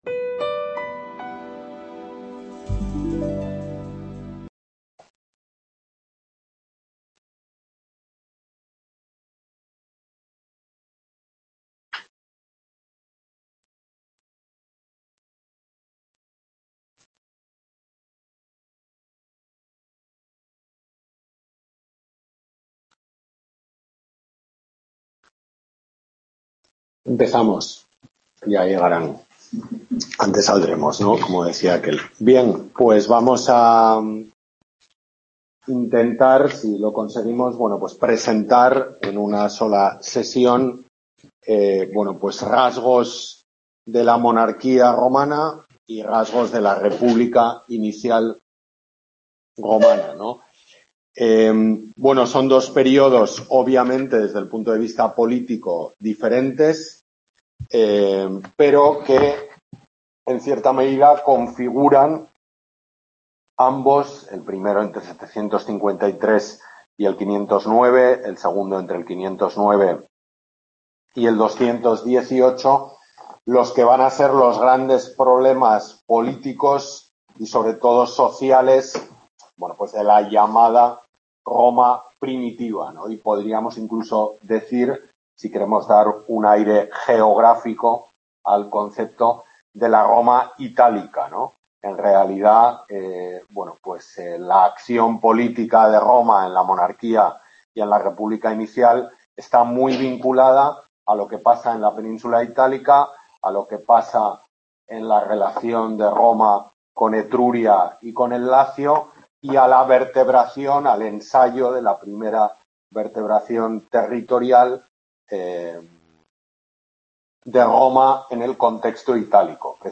Tutoría de Historia Antigua, Grado de Arte, UNED de Pamplona